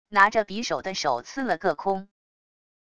拿着匕首的手刺了个空wav音频